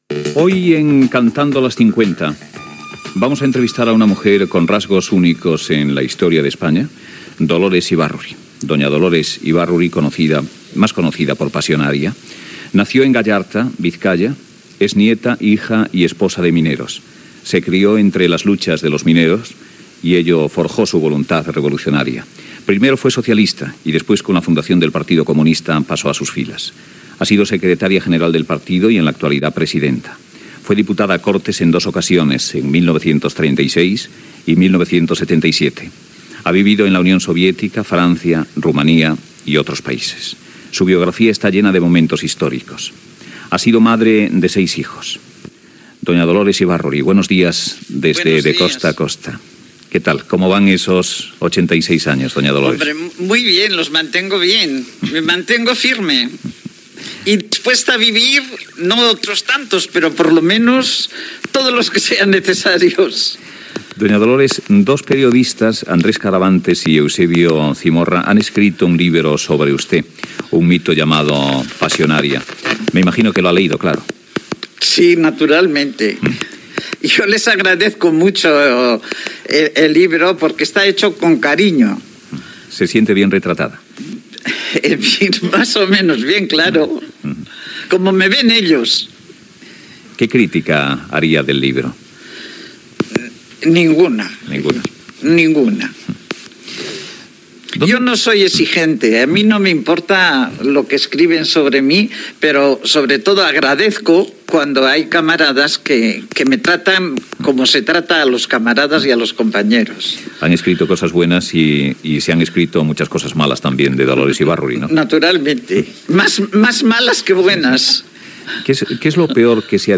Secció "Cantando las cincuenta", amb una entrevista a la política Dolores Ibárruri "la Pasionaria", presidenta del Partido Comunista de España
Info-entreteniment